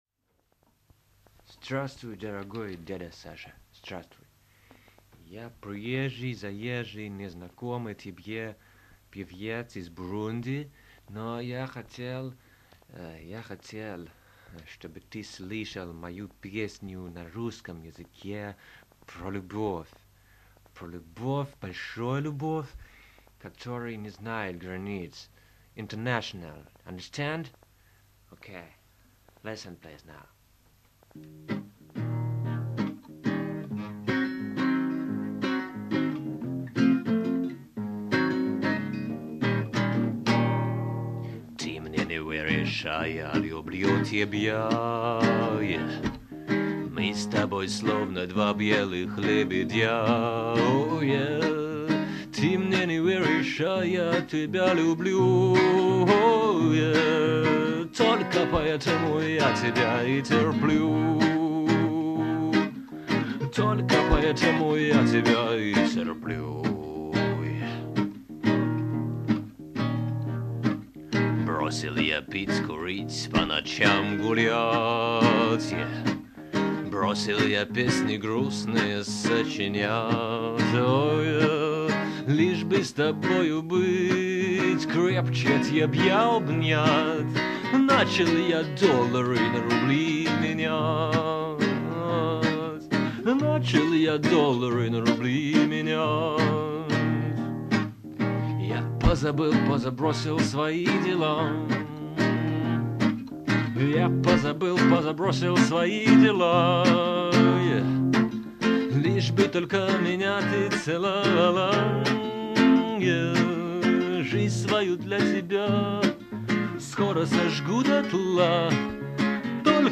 песни 1992-97 гг. в исполнении автора.
ХХ века в собственной мастерской (правда – скульптурной).
дённых в формат mp3, 128kbps, 44kHz, stereo: